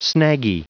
Prononciation du mot snaggy en anglais (fichier audio)
Prononciation du mot : snaggy